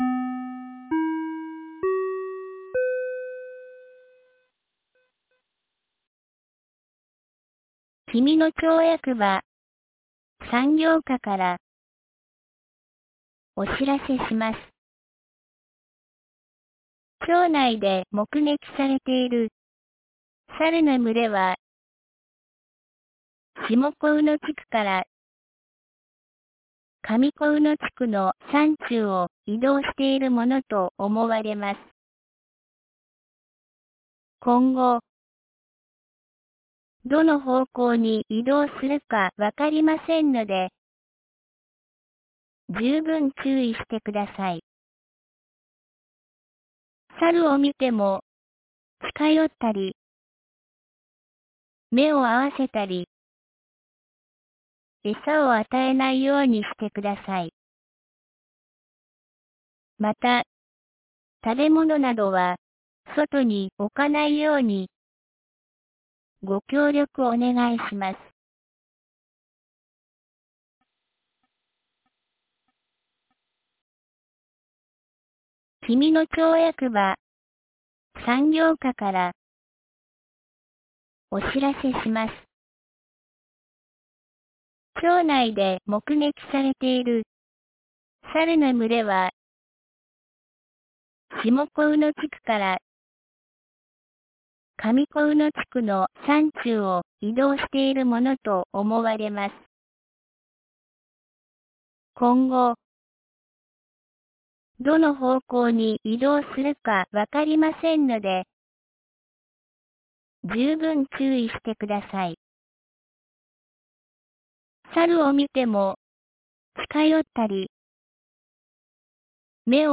2025年08月09日 12時37分に、紀美野町より全地区へ放送がありました。